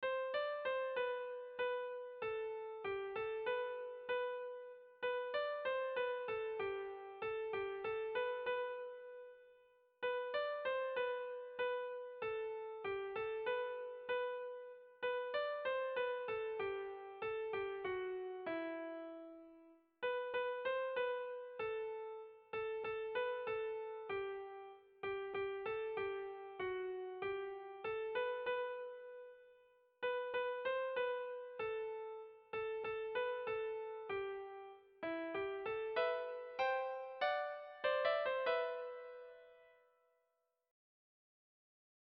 Tragikoa
A1A2B1B2